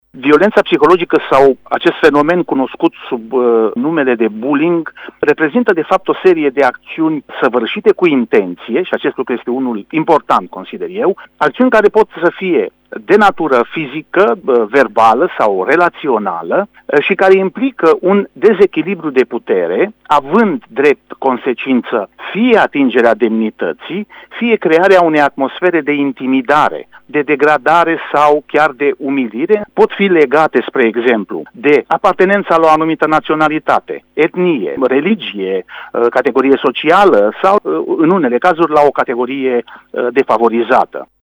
doctor în spihologie: